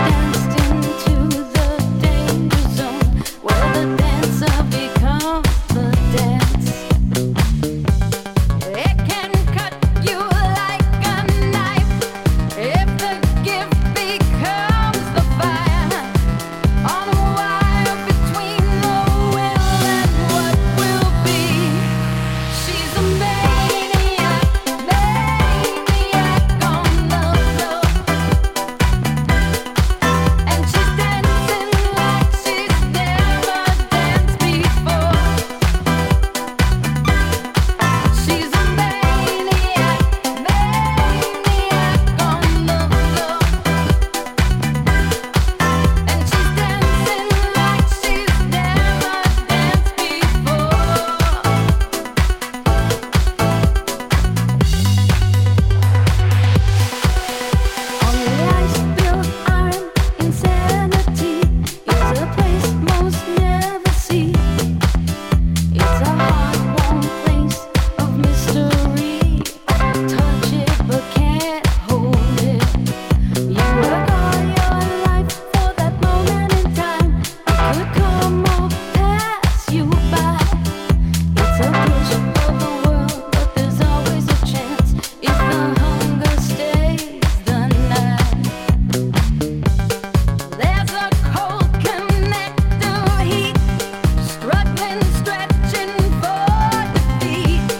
DISCO# NU-DISCO / RE-EDIT